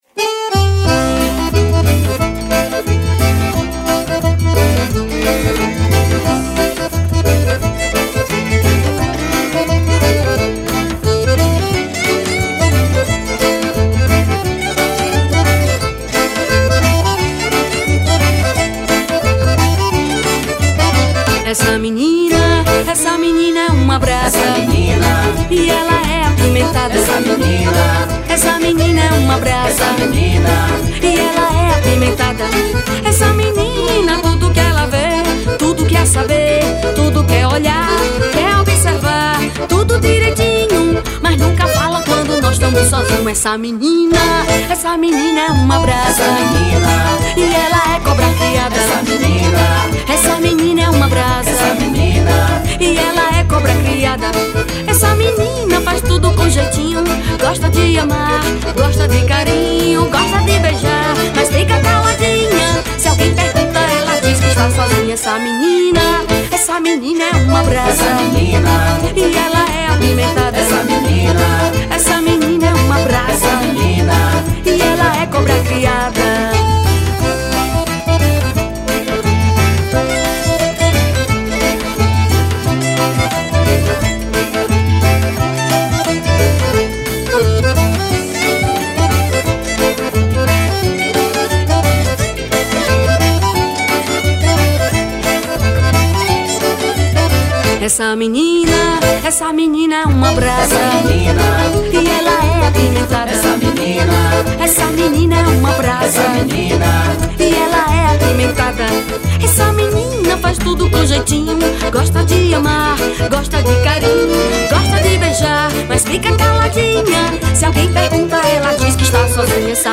• Acordeom
• Violino
• Zabumba
• Triângulo e percussão
• Baixo e cavaquinho
• Backing Vocal